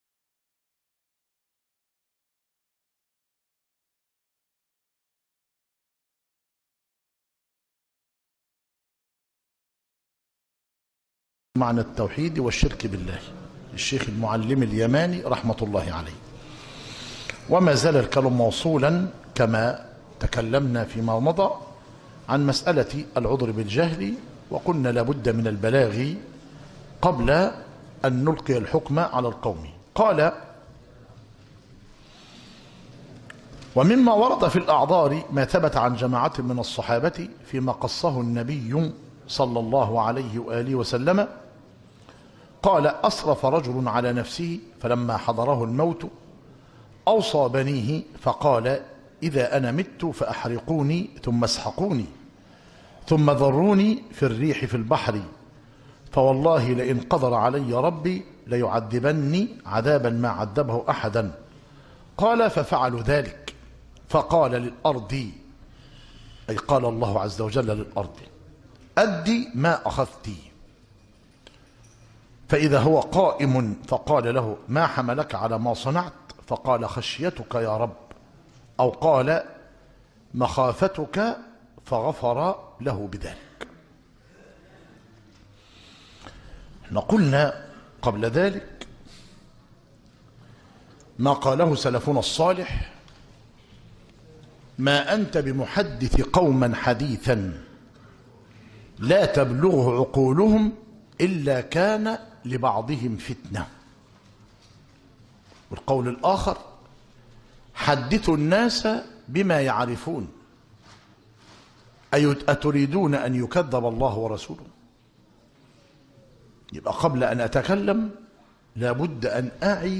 مسجد التوحيد - ميت الرخا - زفتى - غربية - المحاضرة الثانية عشر